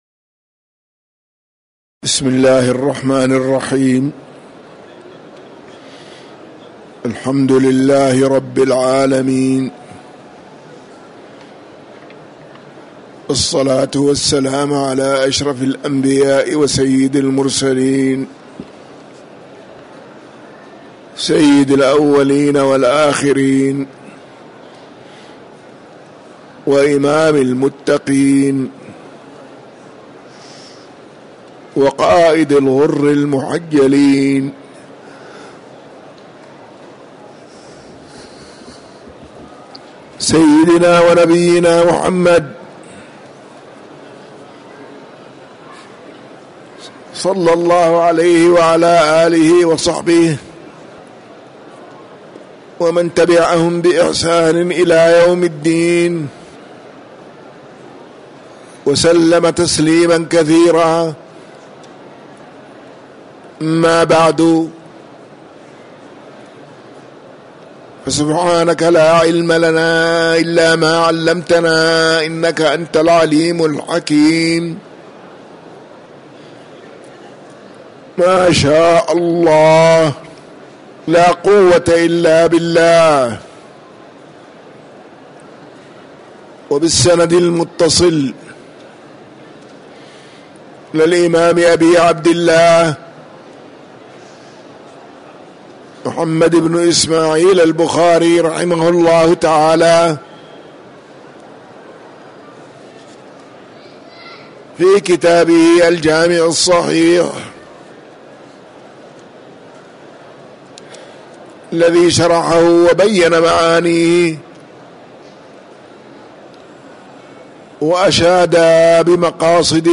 تاريخ النشر ٢٥ شعبان ١٤٤٥ هـ المكان: المسجد النبوي الشيخ